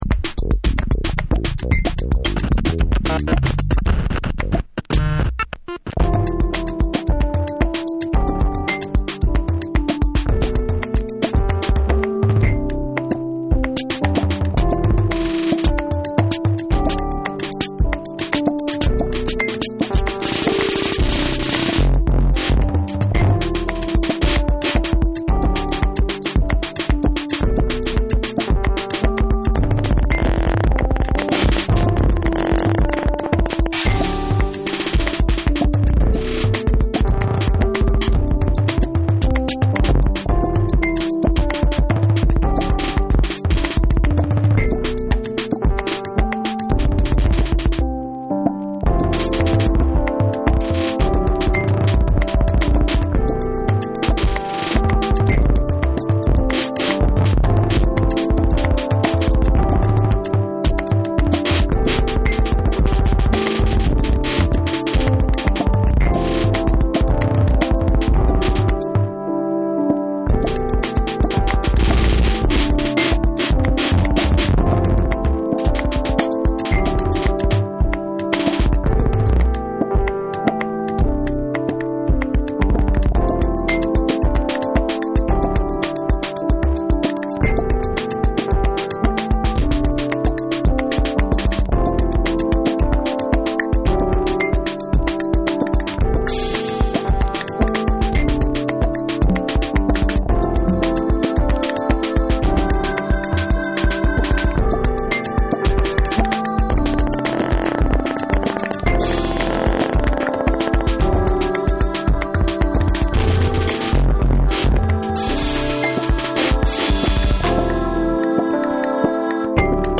存在感のあるタイトなビートに浮遊感のある上音やヴォイス・サンプルを絡めたジャジー・ブレイクビーツ